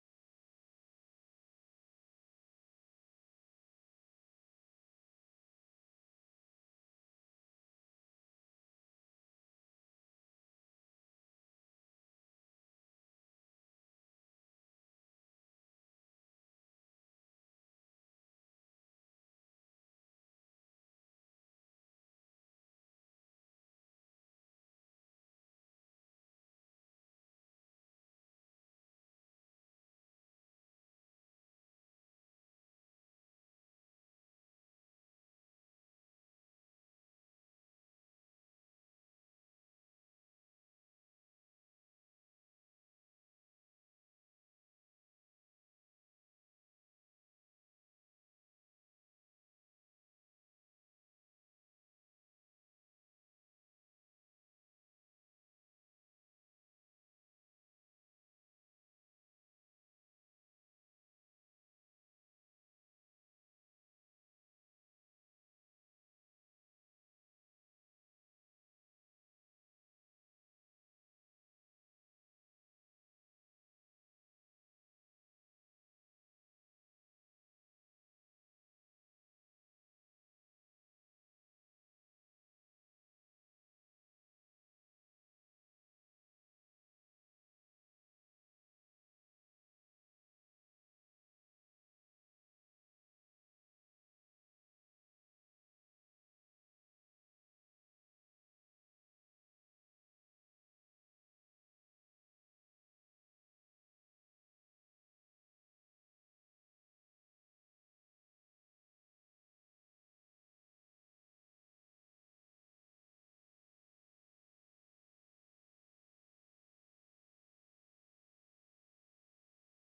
Jeudi 19 mai - Philharmonie, salle de conférence 11h15 Espaces politiques de l’art : musique, politique et conflit : le rôle critique des pratiques musicales en démocratie